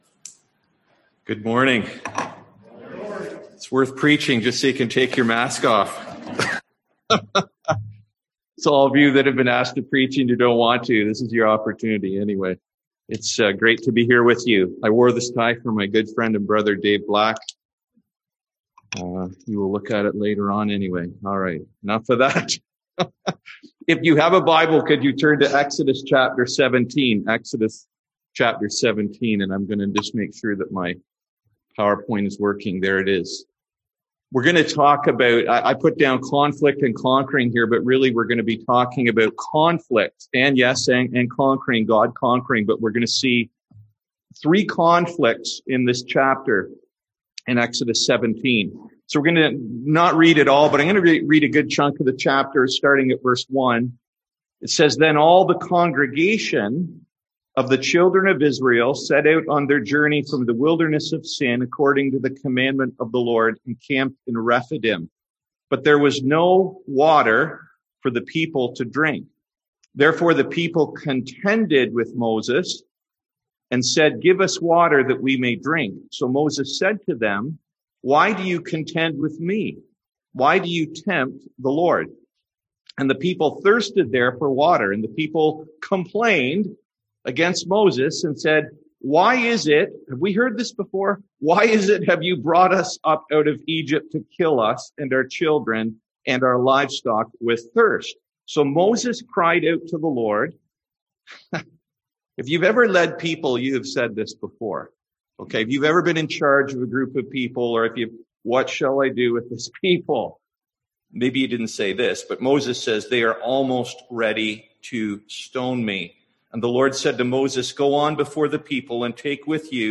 Passage: Exodus 17, 1 Corinthians 10 Service Type: Sunday AM